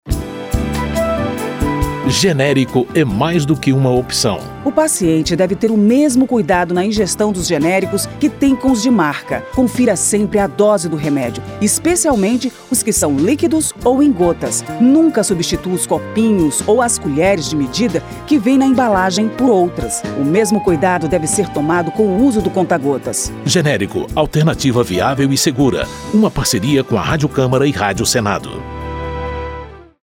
spot-05-parceiras-dosagem.mp3